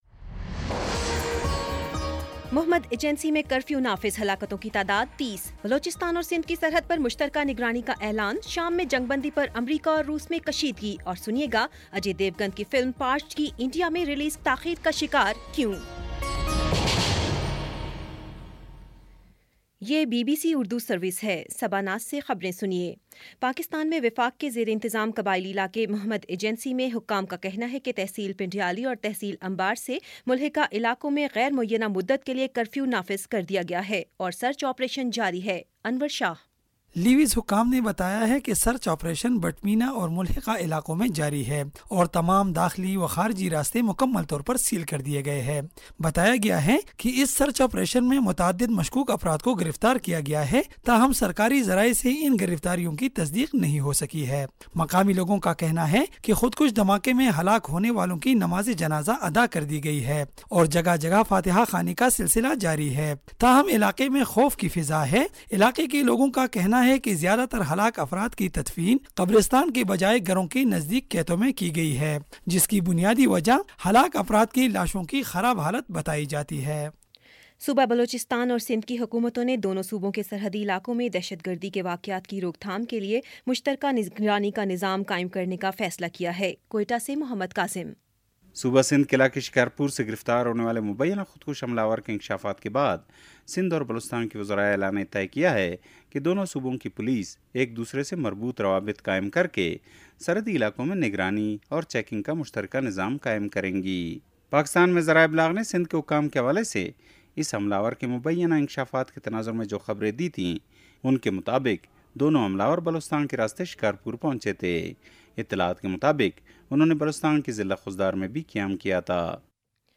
ستمبر17 : شام چھ بجے کا نیوز بُلیٹن